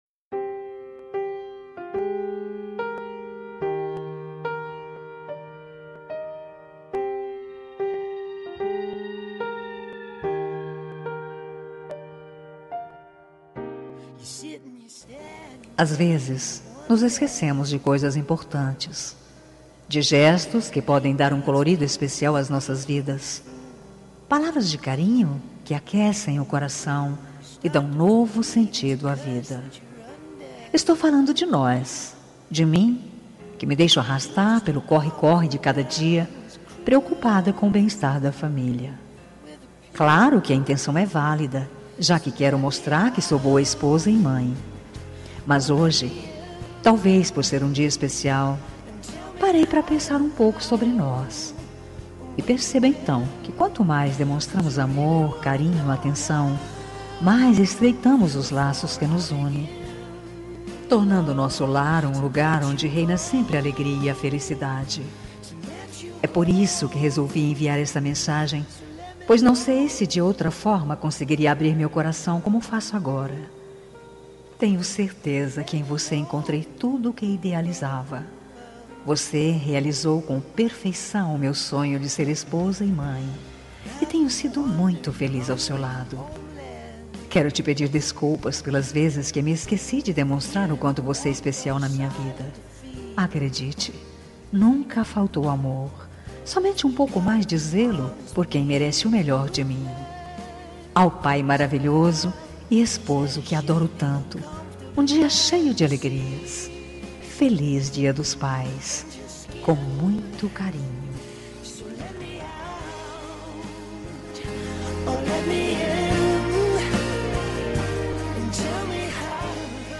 Dia Dos Pais Voz Feminina